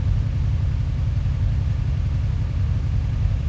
rr3-assets/files/.depot/audio/Vehicles/v12_08/v12_08_idle.wav
v12_08_idle.wav